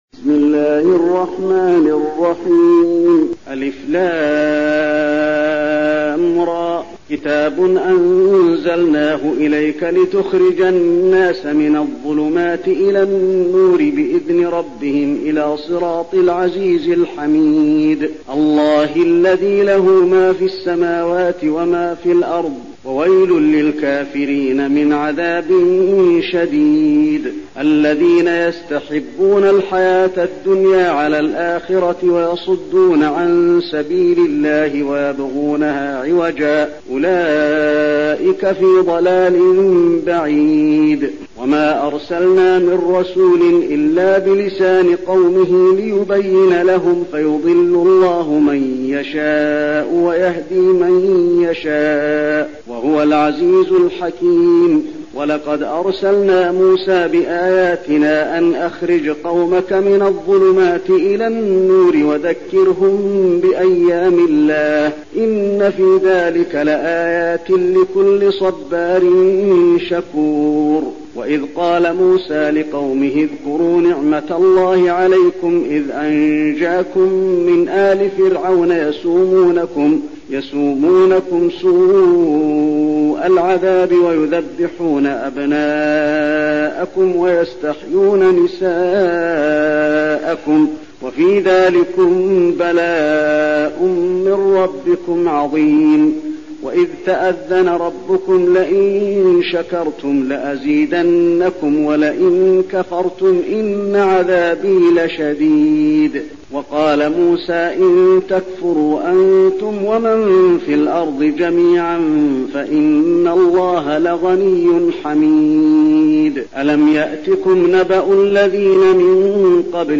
المكان: المسجد النبوي إبراهيم